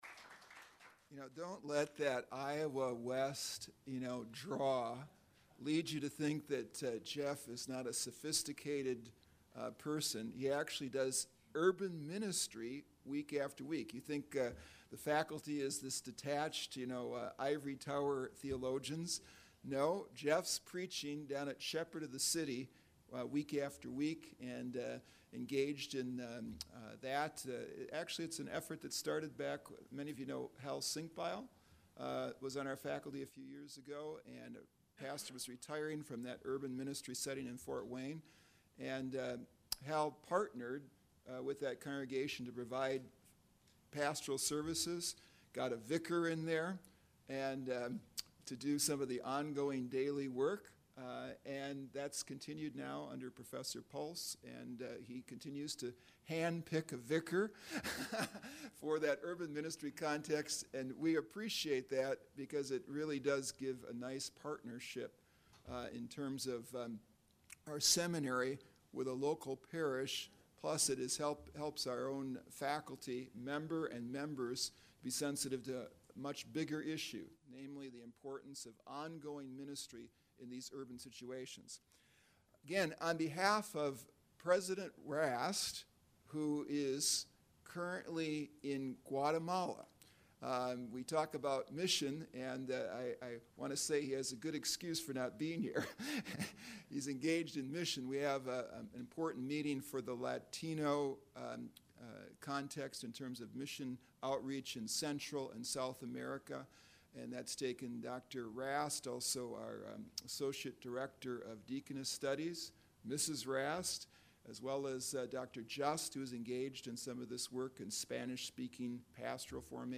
Collections This Item is in 1 Public Collection Urban Ministry Conference: In the World for Good 2013 by CTS This item is in 0 Private Collections Log in to manage and create your own collections.